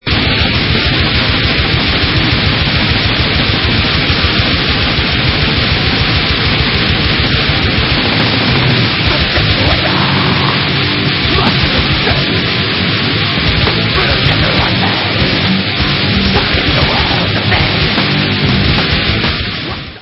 RAW AND DISGUSTING RAGING BLACK DEATH FROM U.K.!
sledovat novinky v oddělení Rock - Speed/Thrash/Death Metal